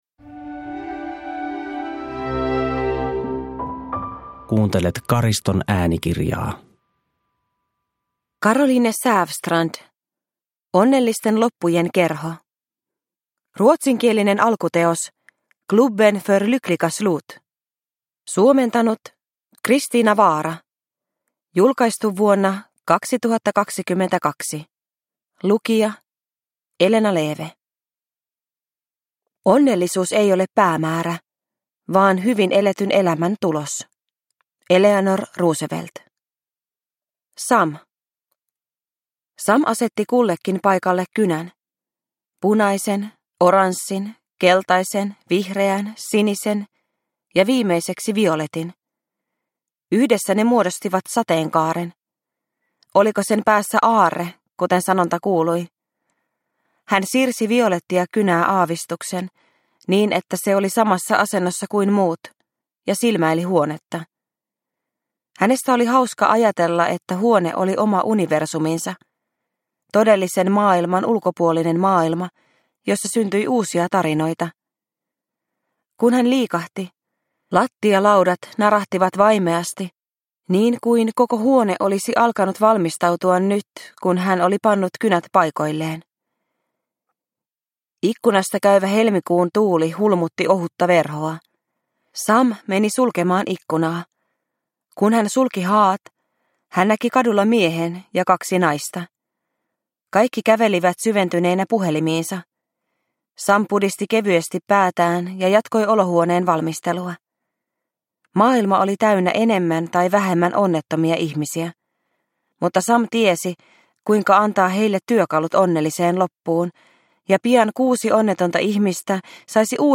Onnellisten loppujen kerho – Ljudbok – Laddas ner
Uppläsare: Elena Leeve